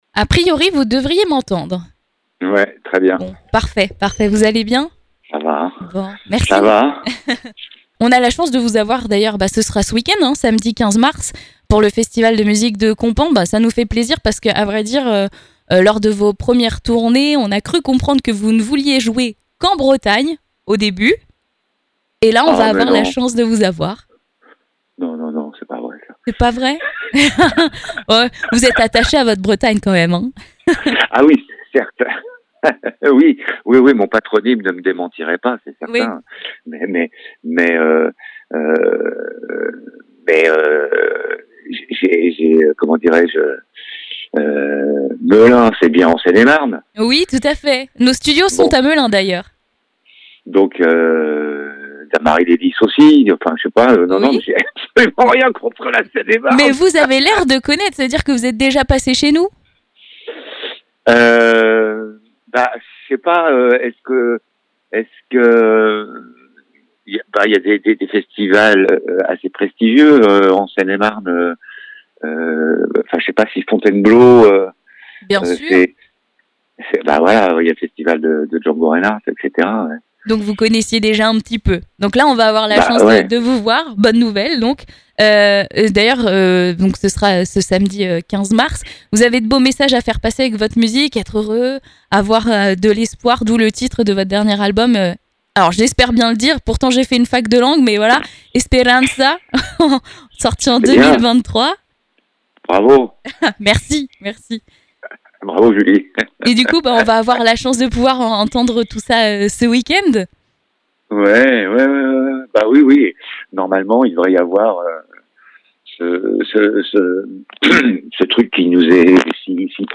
Interview Yvan Le Bolloc'h